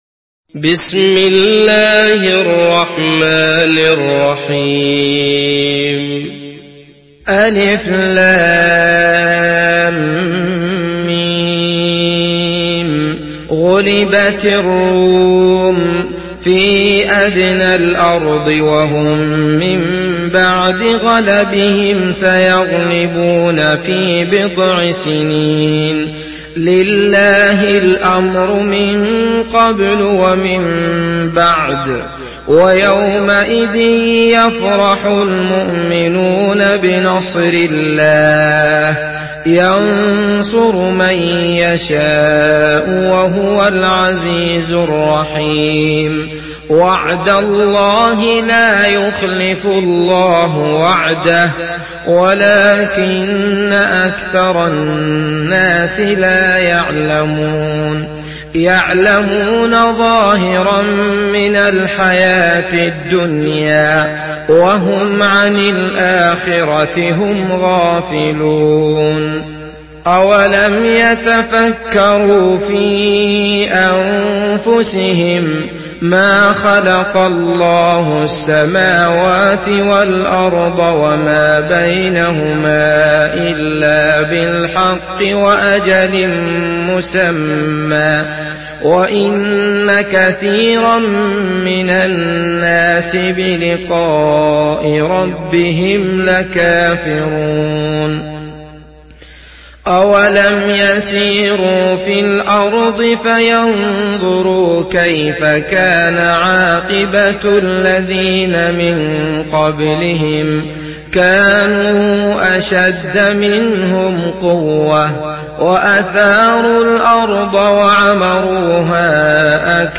Surah Ar-Rum MP3 in the Voice of Abdullah Al Matrood in Hafs Narration
Murattal Hafs An Asim